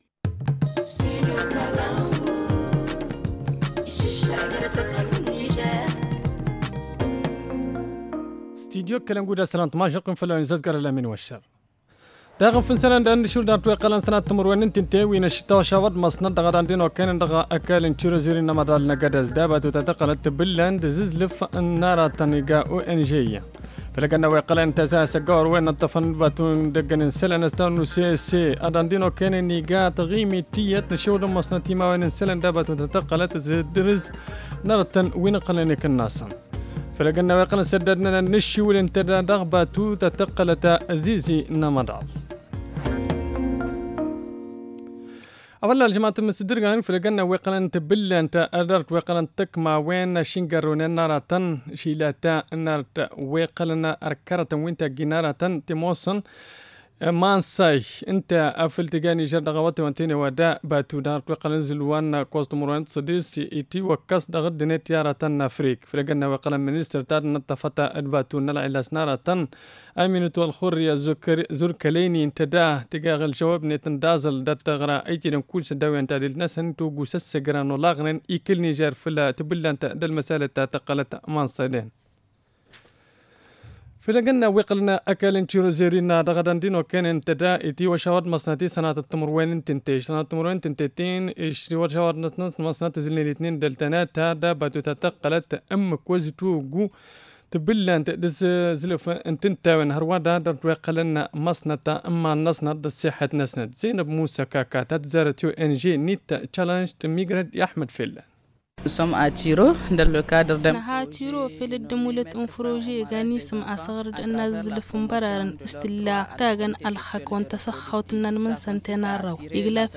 Le journal du 16 juin 2022 - Studio Kalangou - Au rythme du Niger